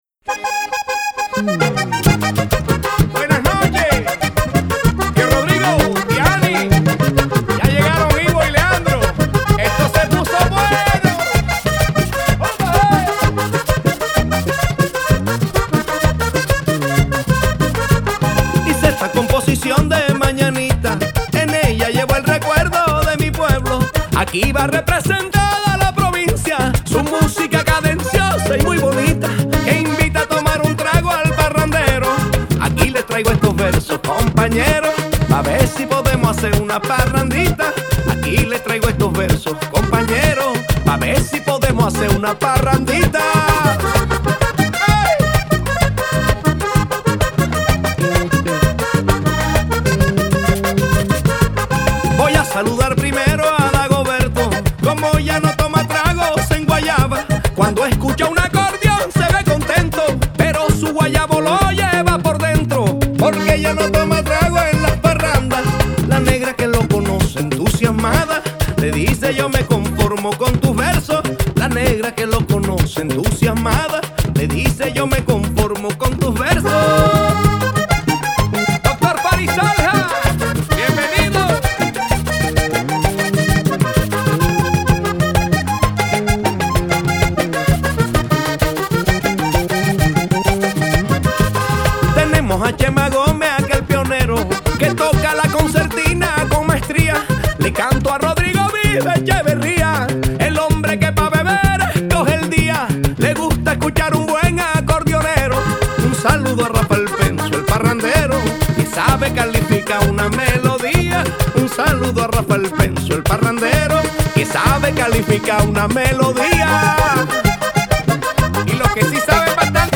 Vallenato